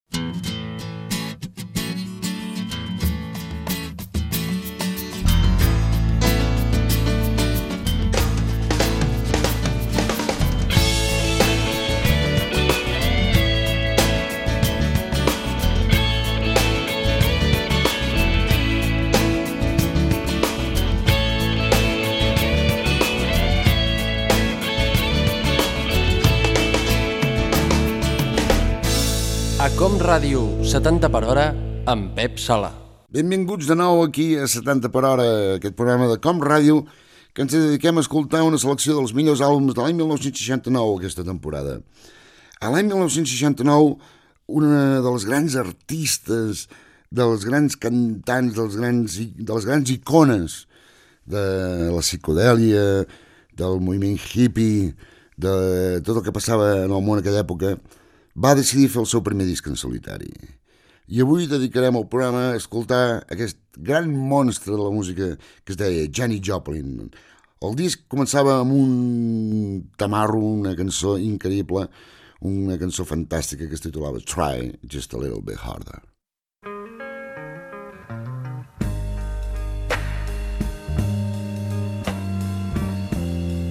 Careta i presentació inicial del programa dedicat a l'any 1969
Musical